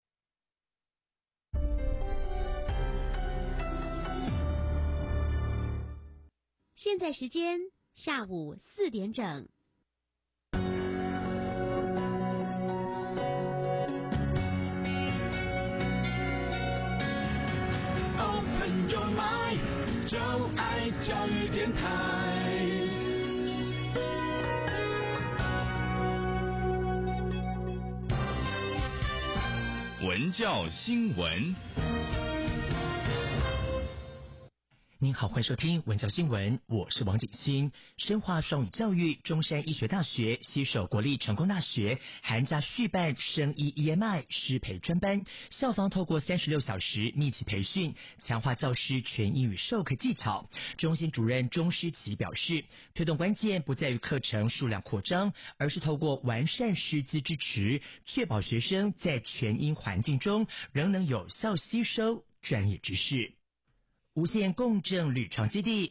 國立教育廣播電台報導：